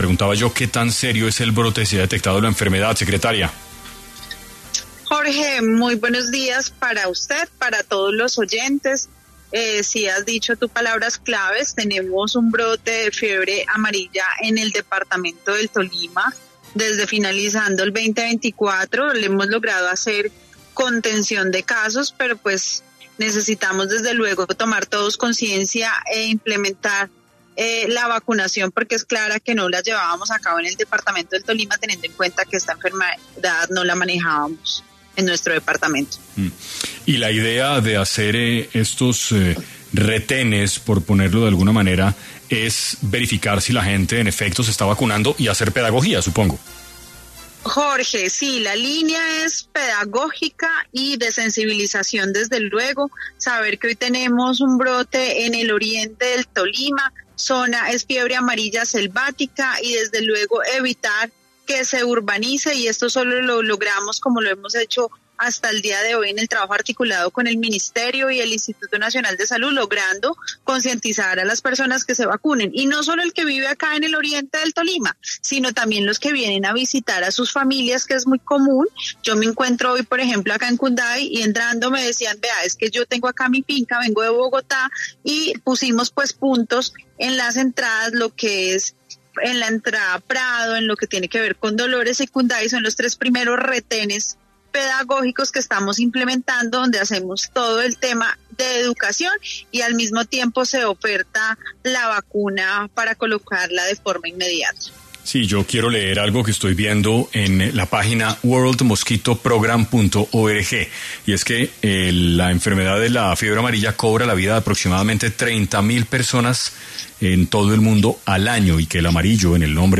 Katherine Rengifo, secretaria de Salud del Tolima, habló hoy para 6AM sobre la importancia de la vacunación contra la fiebre amarilla en el país.